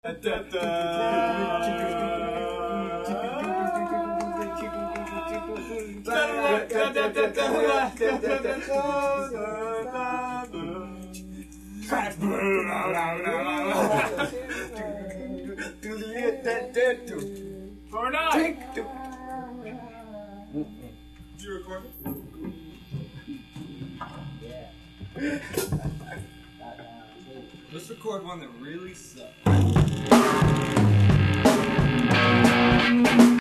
bass
drums
guitar + vocals
also in the backroom and between recording songs